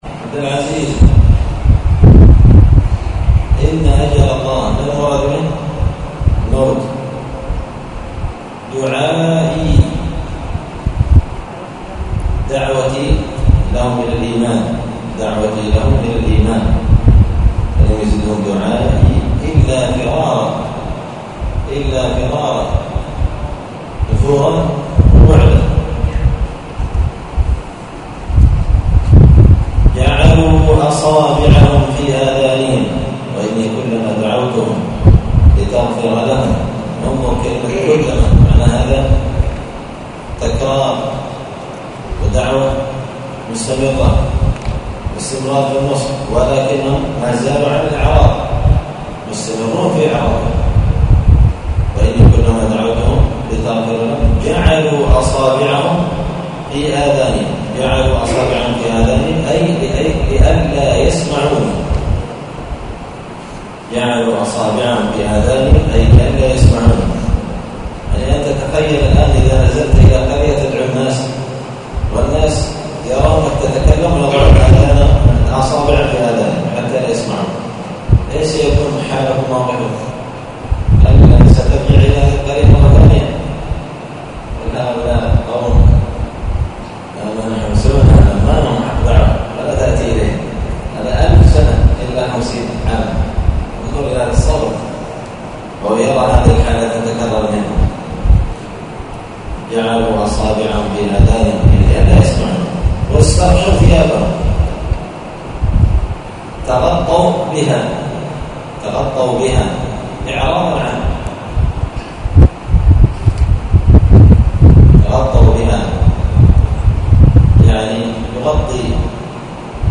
الأحد 12 محرم 1445 هــــ | الدروس، دروس القران وعلومة، زبدة الأقوال في غريب كلام المتعال | شارك بتعليقك | 10 المشاهدات
80الدرس-الثمانون-من-كتاب-زبدة-الأقوال-في-غريب-كلام-المتعال.mp3